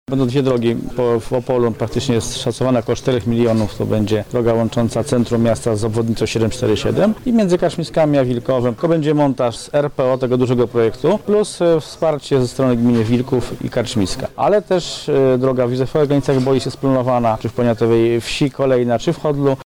• mówi starosta Powiatu Opolskiego, Zenon Rodzik.